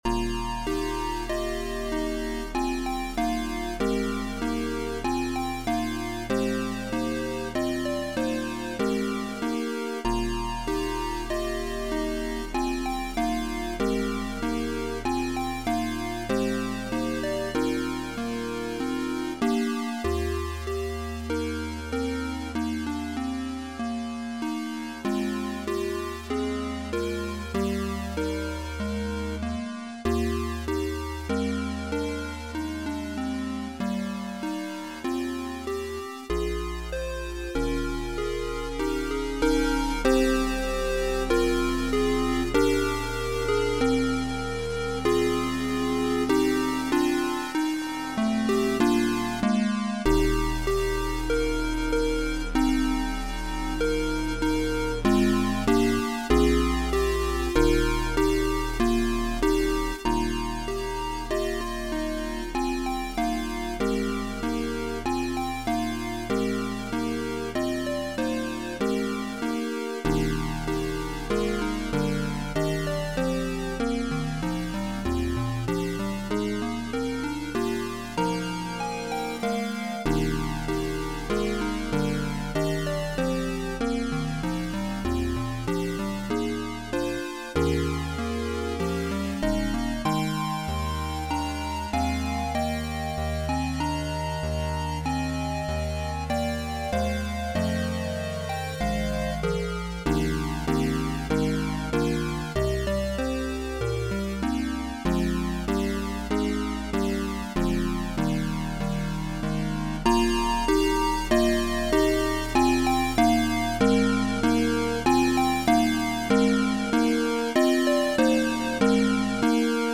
Voicing/Instrumentation: Organ/Organ Accompaniment We also have other 25 arrangements of " I Feel My Savior's Love ".